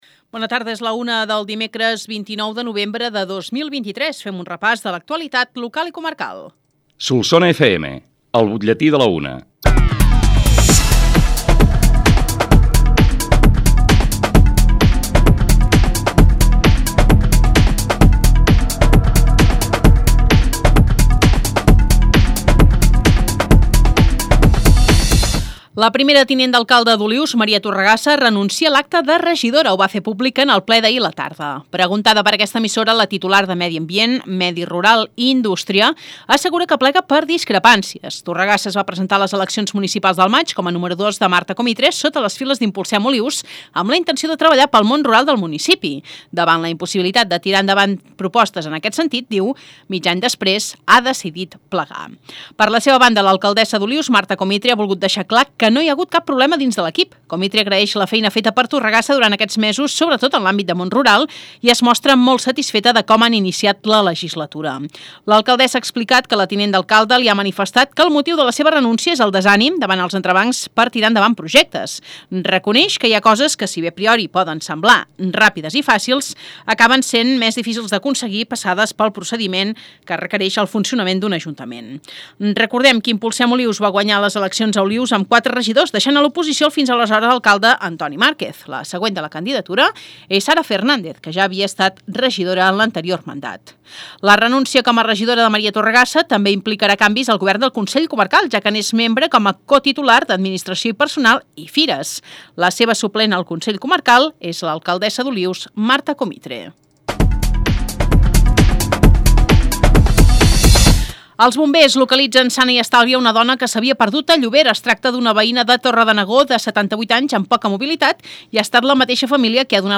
L’ÚLTIM BUTLLETÍ